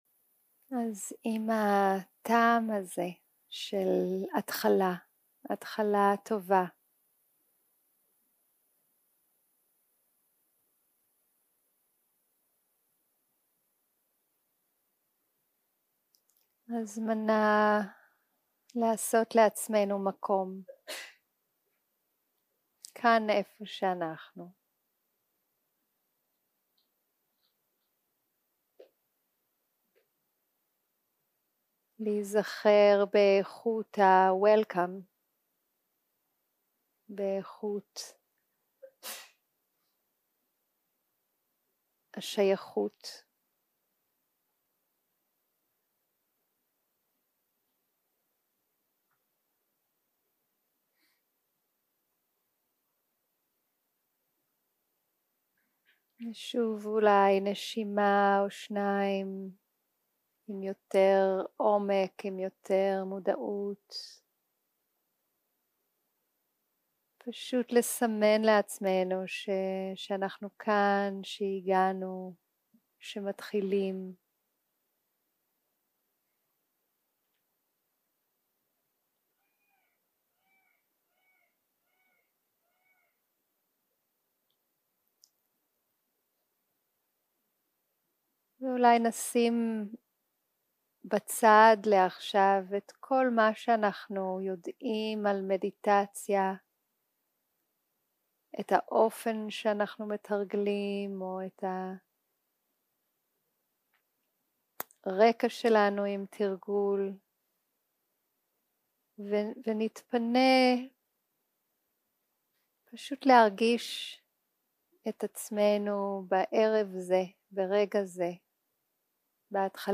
יום 1 - הקלטה 1 - ערב - מדיטציה מונחית
סוג ההקלטה: מדיטציה מונחית